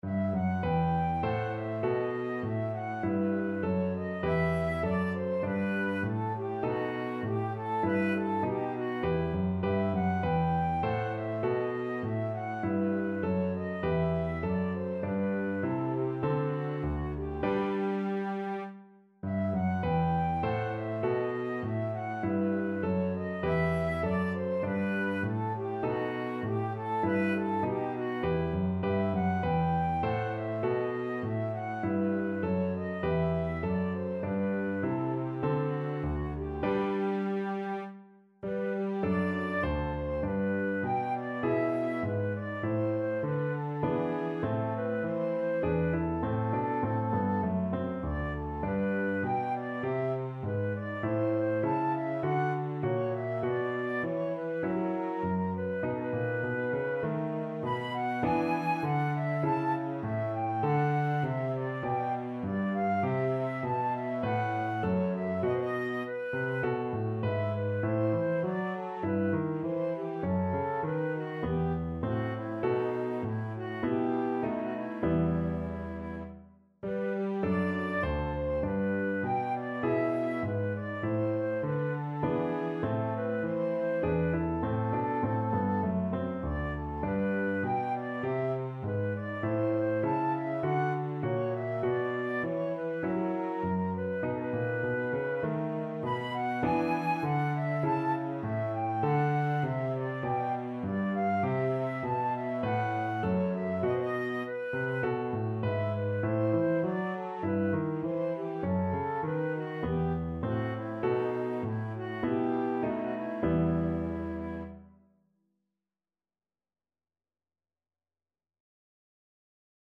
has been arranged for flute and piano (with cello continuo).